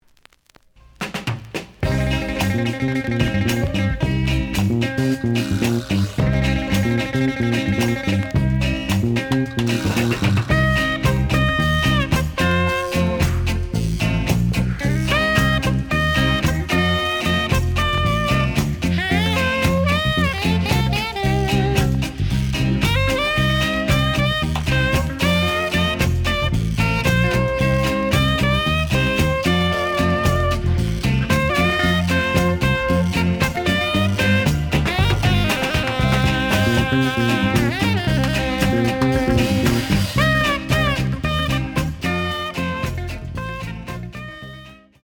The audio sample is recorded from the actual item.
●Genre: Jazz Other